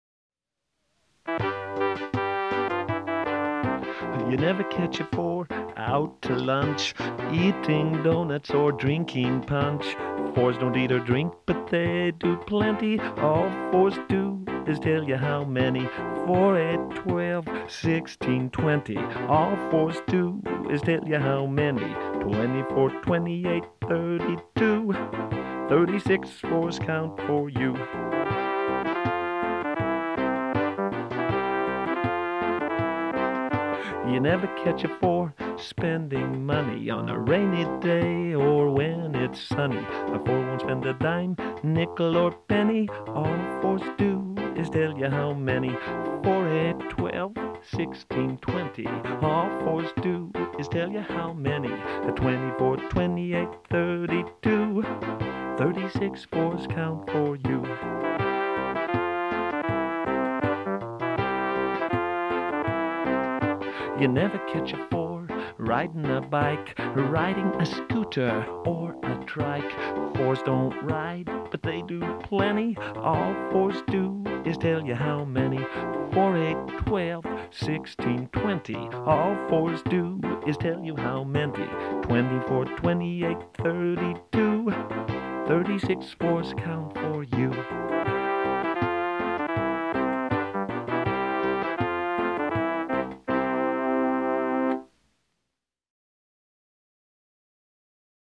skip count song